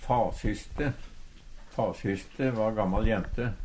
fasyste - Numedalsmål (en-US)